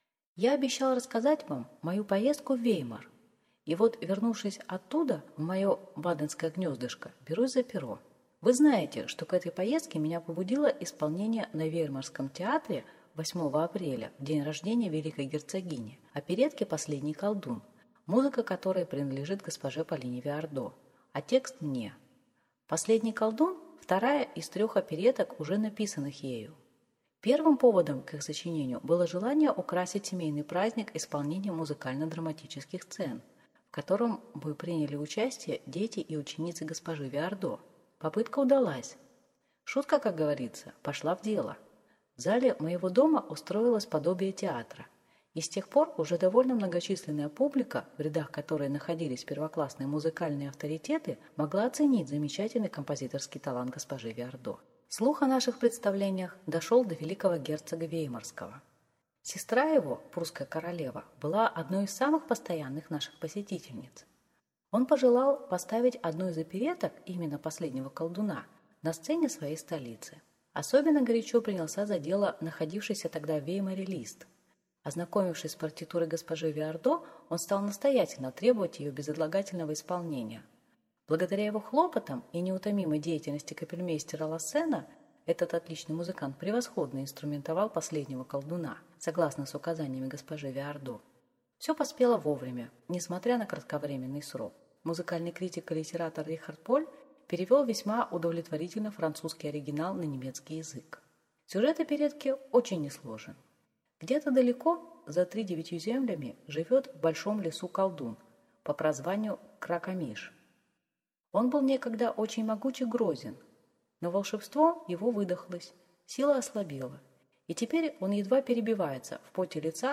Аудиокнига Первое представление оперы г-жи Виардо в Веймаре | Библиотека аудиокниг